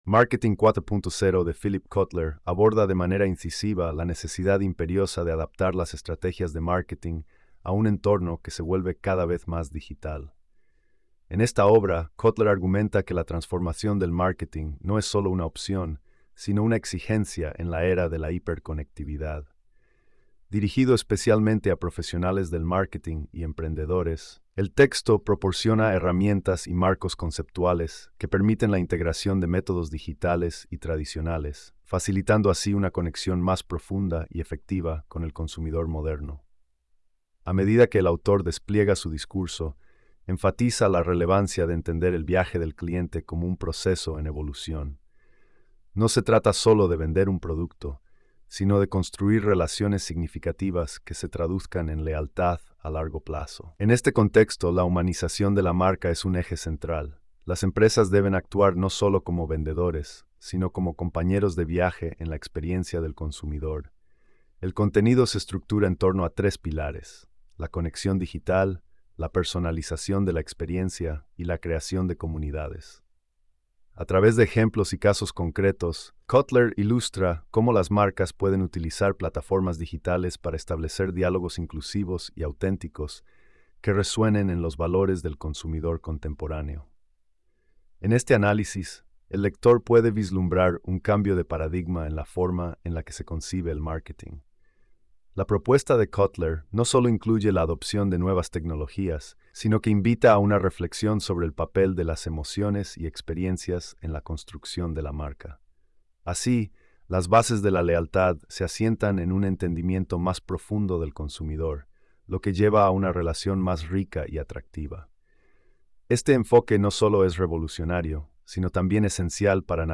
Marketing 4.0 – Audiolibros Resumidos en Español